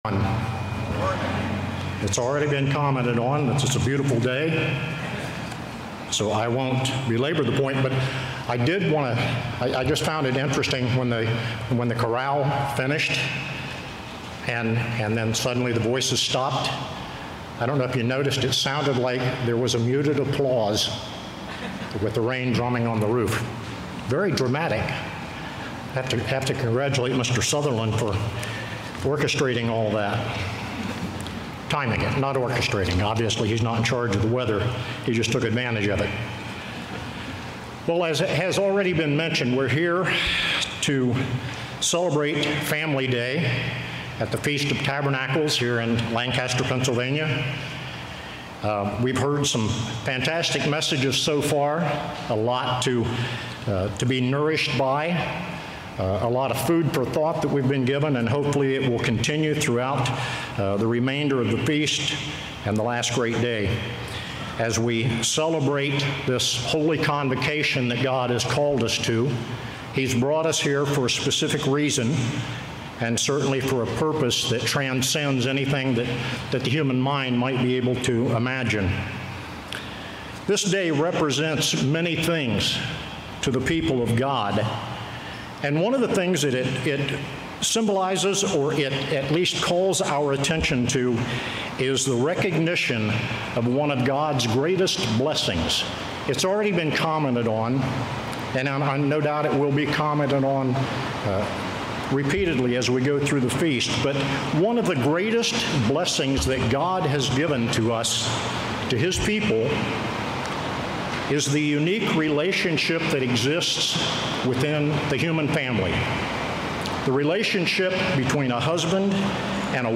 This sermon was given at the Lancaster, Pennsylvania 2021 Feast site.